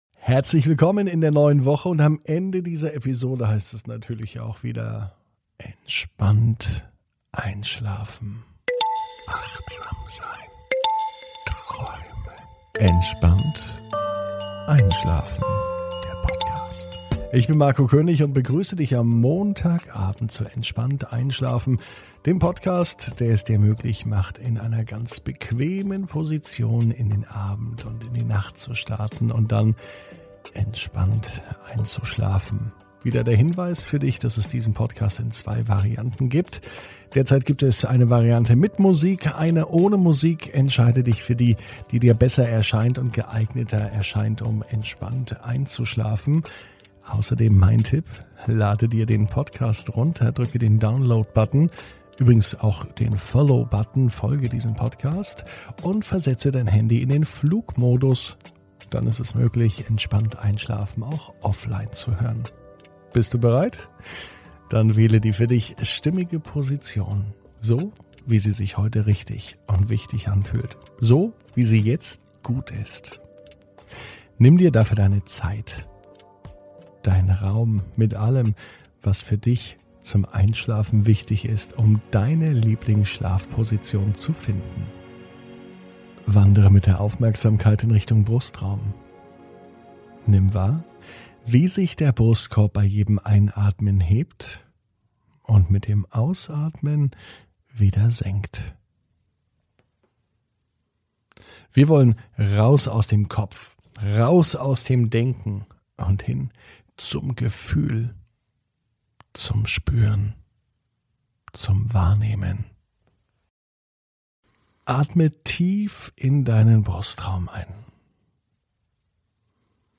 (ohne Musik) Entspannt einschlafen am Montag, 31.05.21 ~ Entspannt einschlafen - Meditation & Achtsamkeit für die Nacht Podcast